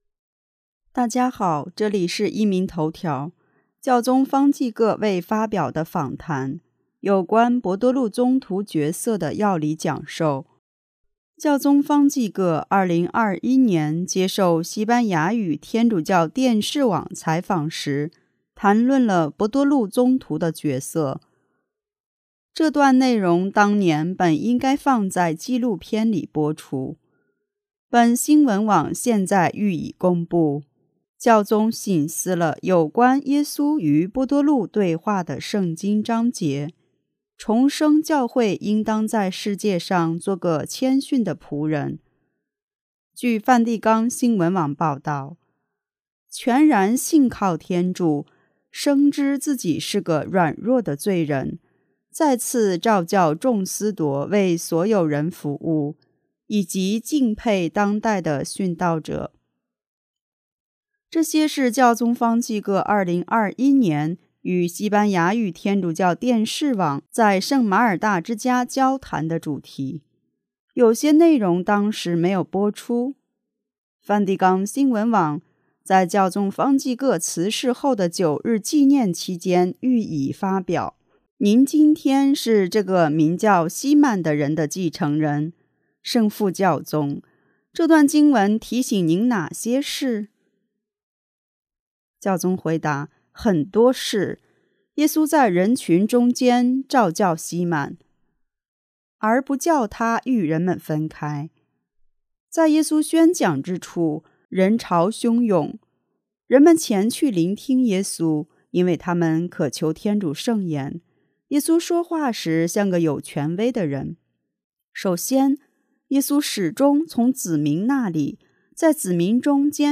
【壹明头条】|教宗方济各未发表的访谈：有关伯多禄宗徒角色的要理讲授